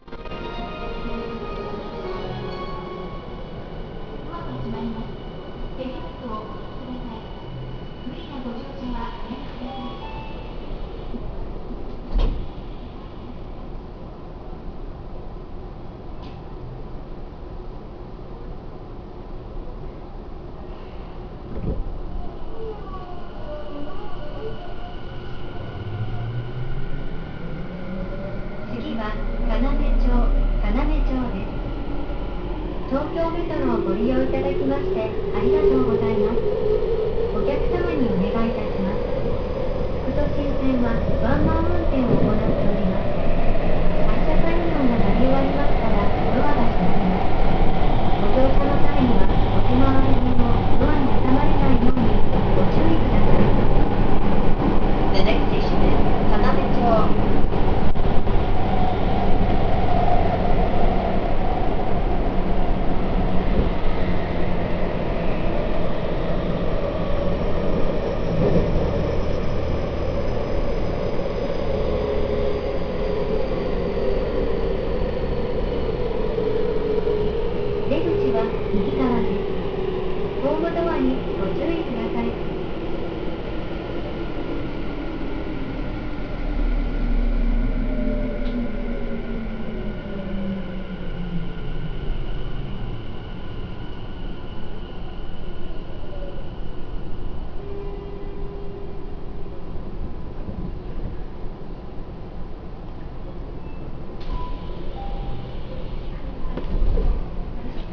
・5050系（後期車）走行音
【東京ﾒﾄﾛ副都心線】千川→要町（1分47秒：587KB）